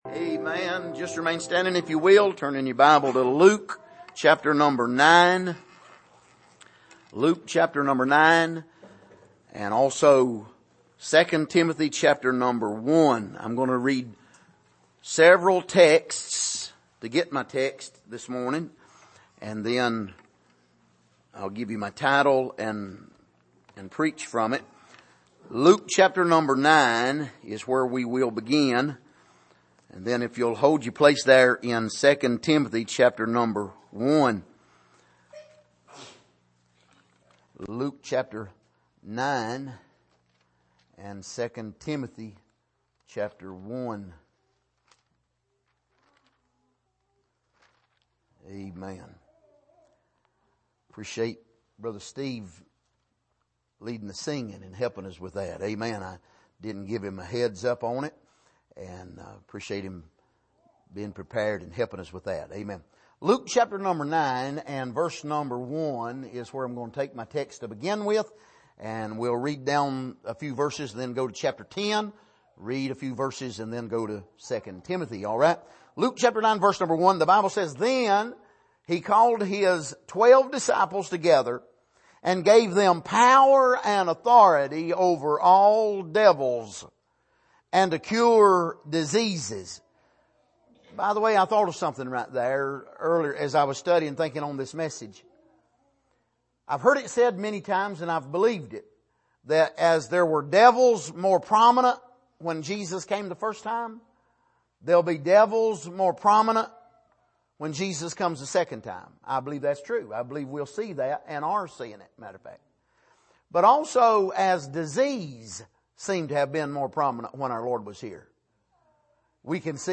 Passage: Luke 9:1-6 Service: Midweek